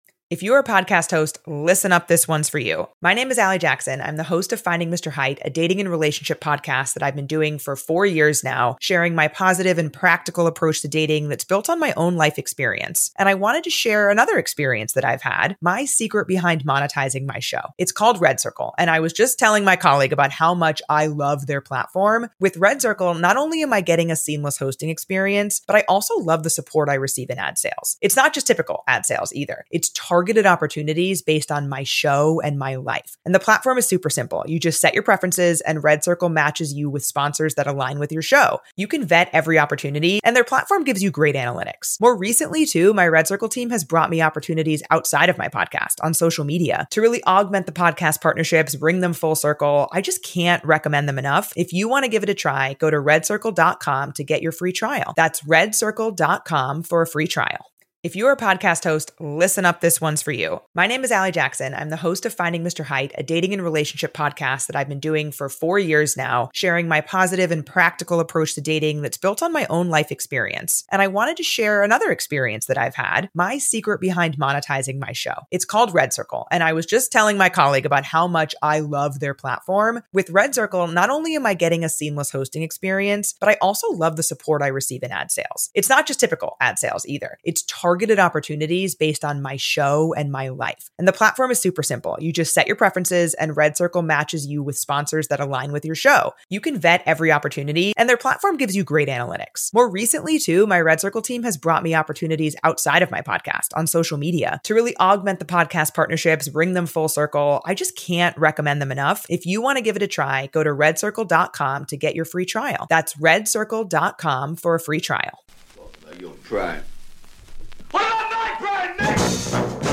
An academic and a comedian review film and television.